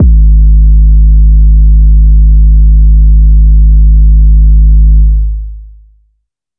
• phonk kicks oneshot 2 - 808 G#.wav
Specially designed for phonk type beats, these nasty, layered 808 one shots are just what you need, can also help designing Hip Hop, Trap, Pop, Future Bass or EDM. Enjoy these fat, disrespectful 808 ...
phonk_kicks_oneshot_2_-_808_G_sharp__xHF.wav